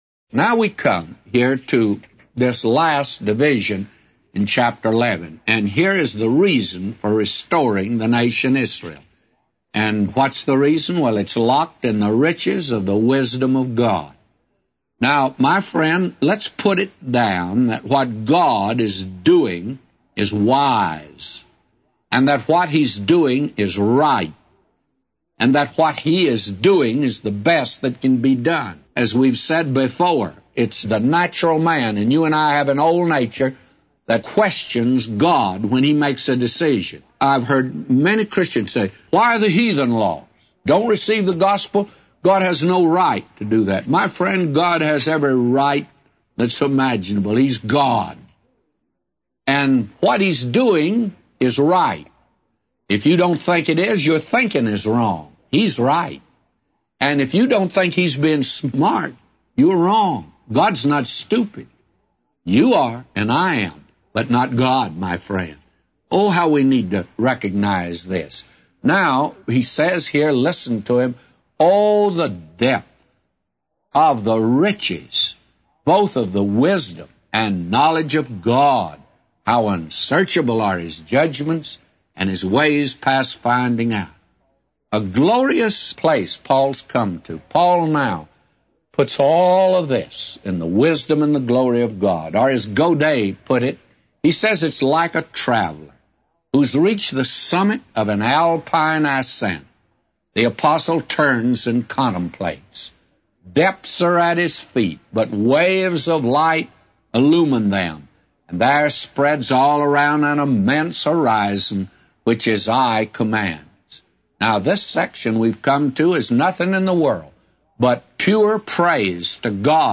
A Commentary By J Vernon MCgee For Romans 11:33-36